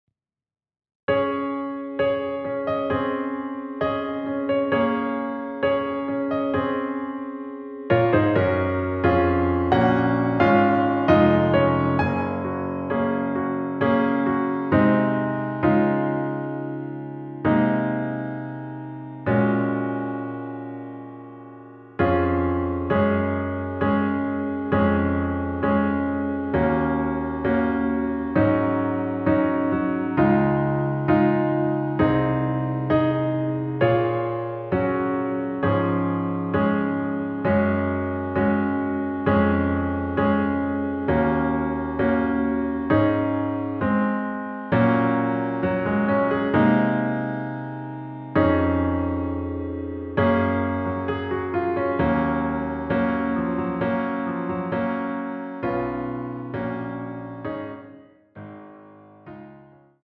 반주가 피아노 하나만으로 되어 있습니다.(미리듣기 확인)
원키 피아노 버전 MR입니다.
Db